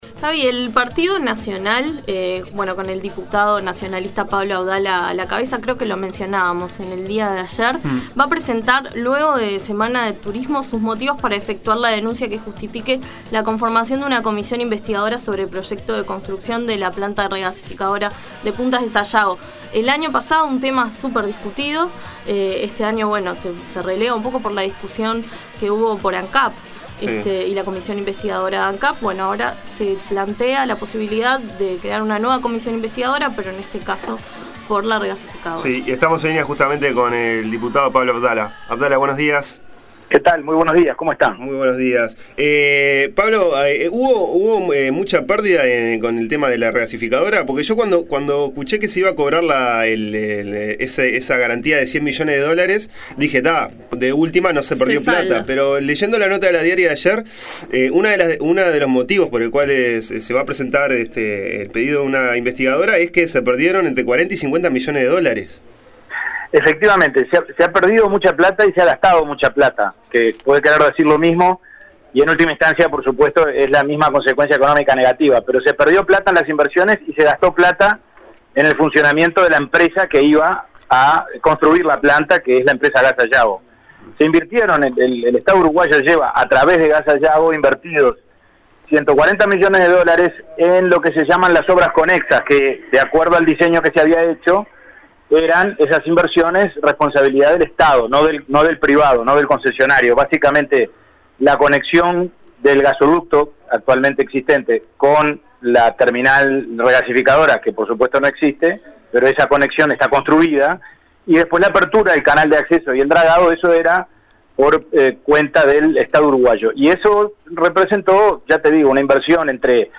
El diputado Abdala señaló a La mañana de Uni Radio que con el proyecto, que permanece inconcluso, ya se perdieron 40 millones de dolares y que aspectos como la concesión al consorcio GNLS cuando no era la mejor oferta o la supuesta violación de permisos ambientales son aspectos que merecen ser investigados.